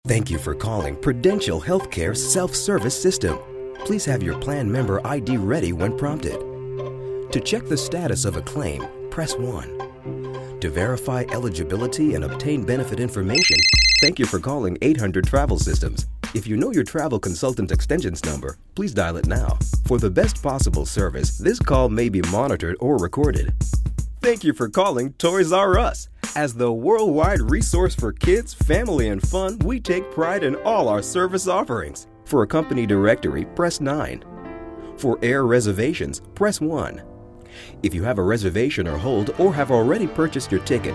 native voice artist in English
High Baritone and clear Voice